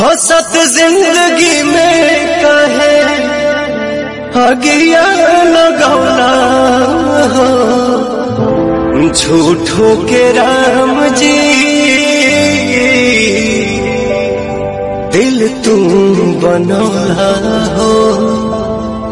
Category: Bhojpuri Ringtones